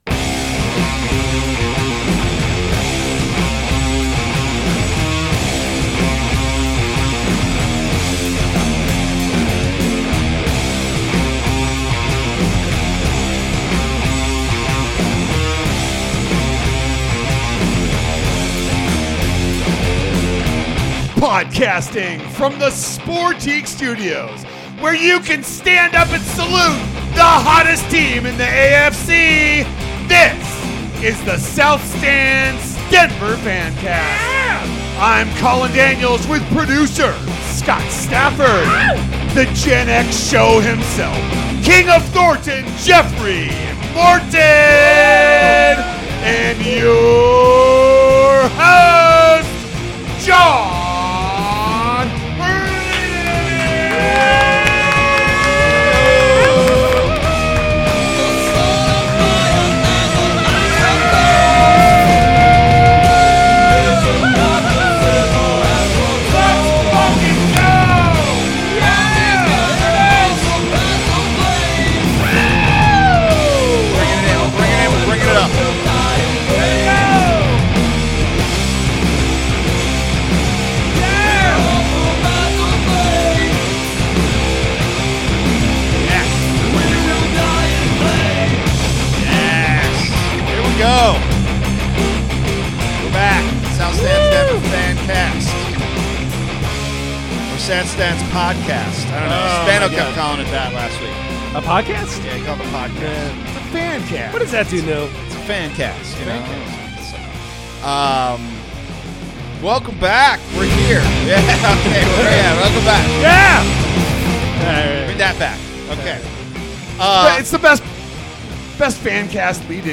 Plenty of laughs in this one